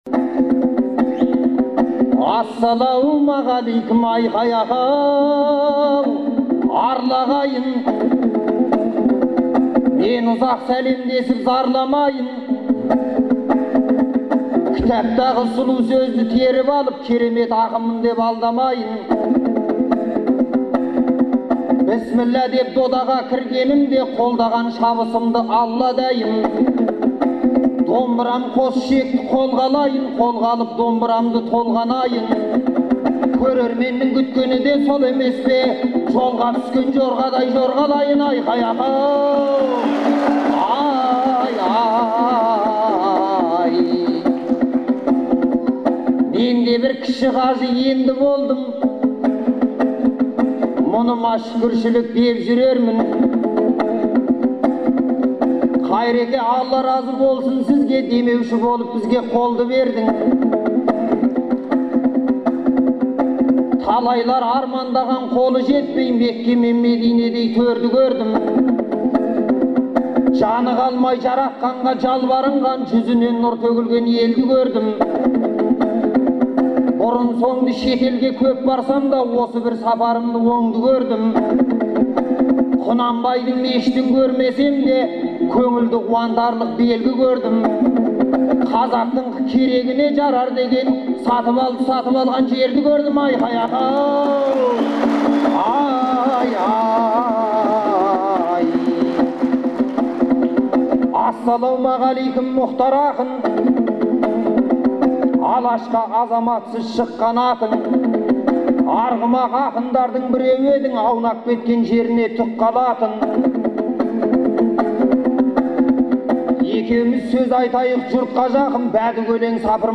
Алматыда «Нұр Отан» партиясының хатшысы Қайрат Сатыбалдының қаржылай демеуімен «Төртеу түгел болса» атты ақындар айтысы өтті.
Алматы, 2 мамыр 2011 жыл.